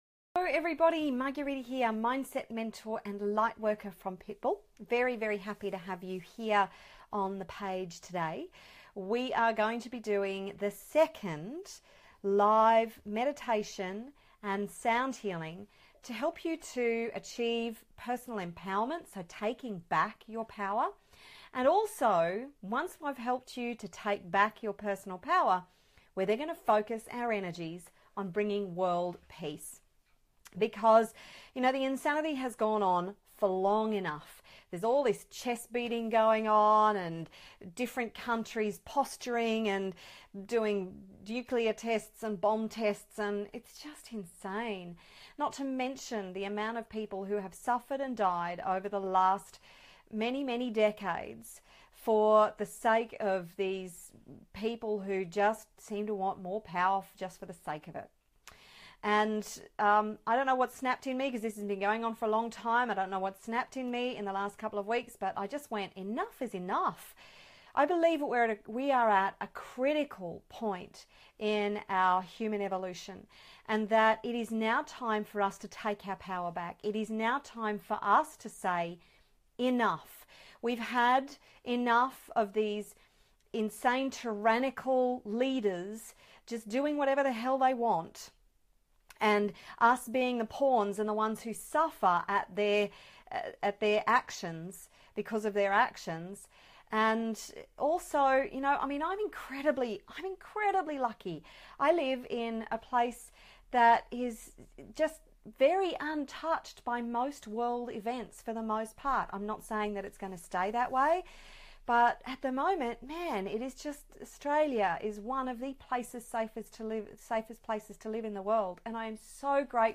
Sound Healing and Meditation for World Peace and Empowerment – Pitbull Mindset